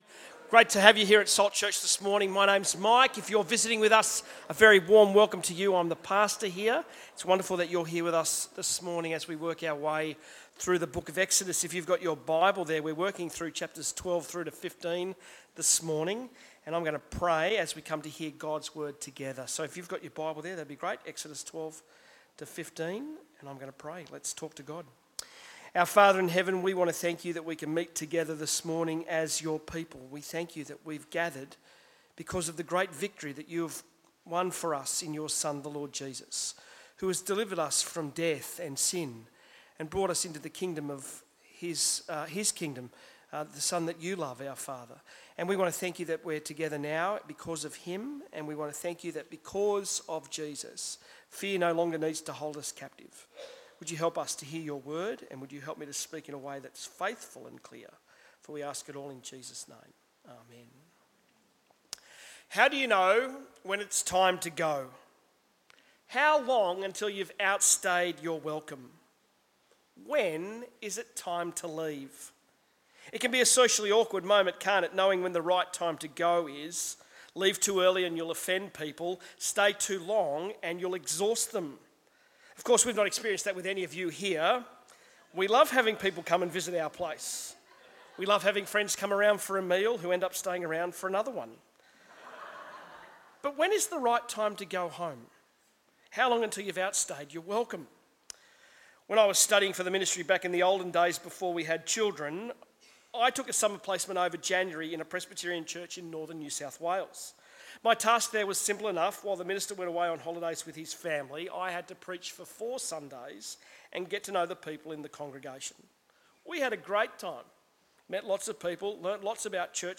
Sermons
Bible talk on Exodus from God's dangerous Deliverance series.